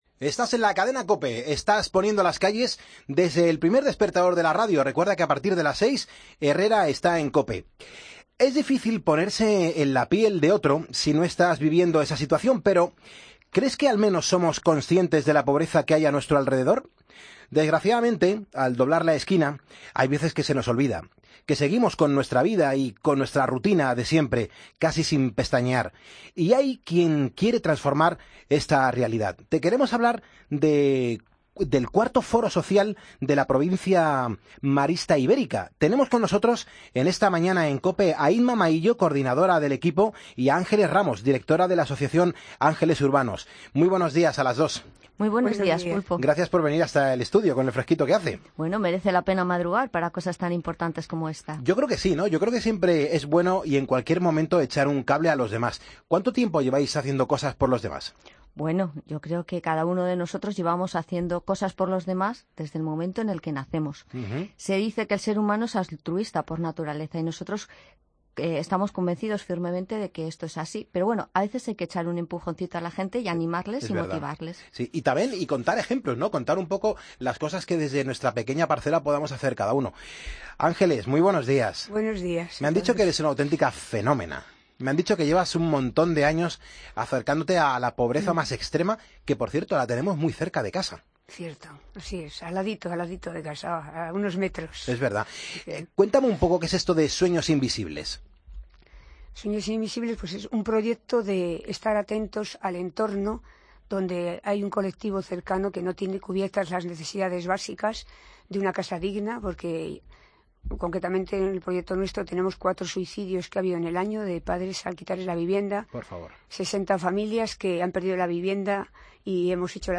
Hablamos en el estudio